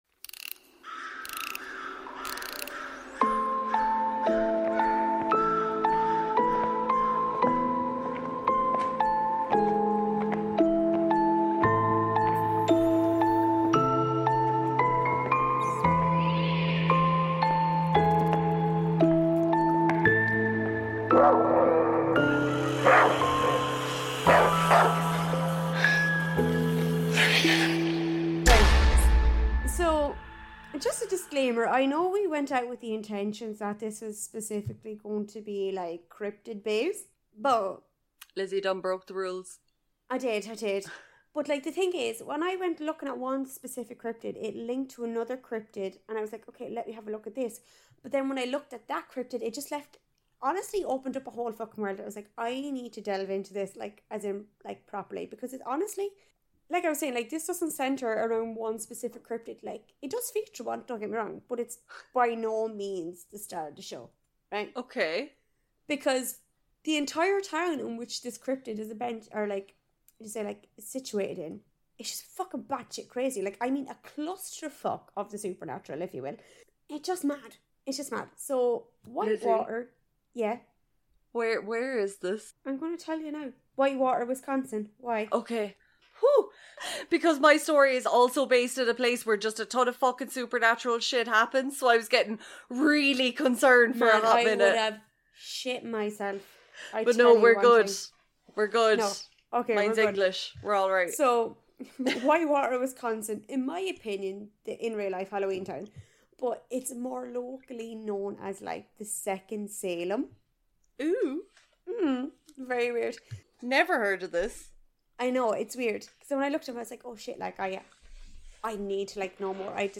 Tune in for more slurred speech, drunk rambles, questionable stories.